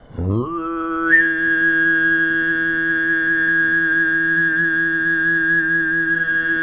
TUV1 DRONE07.wav